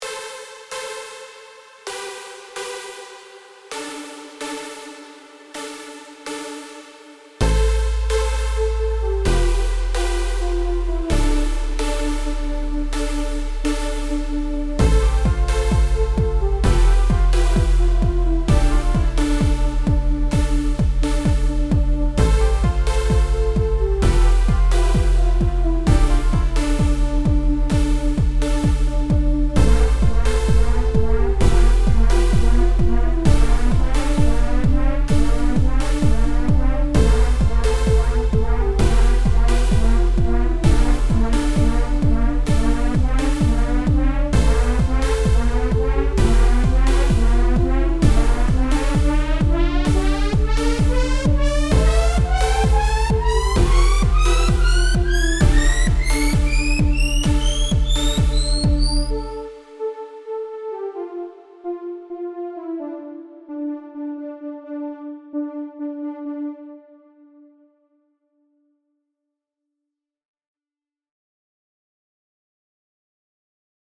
Both were produced and mixed in Logic Pro.
Trance Riser - C Major, 130bpm
Inspired initially by a descending broken D minor chord, I recorded into Logic and added various layers with funky synth sounds, 6 in total. This one uses a wide range of sounds throughout.